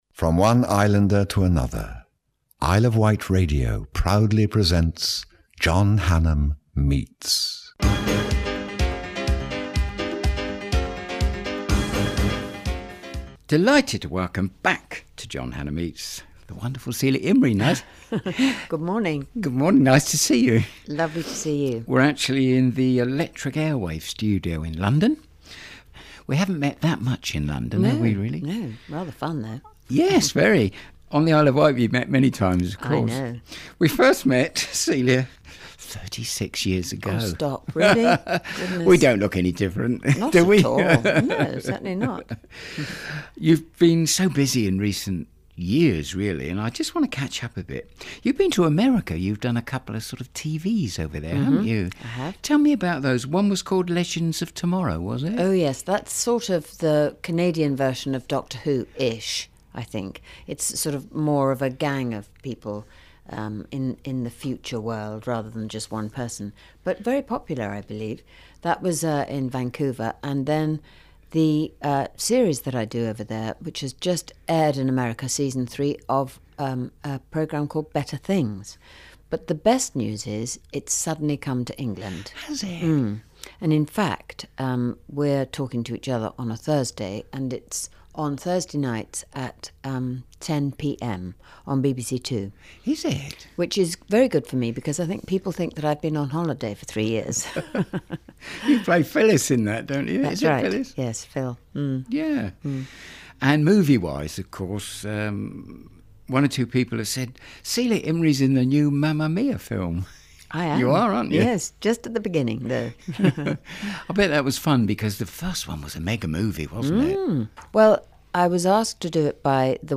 interviews celebrities on their life and work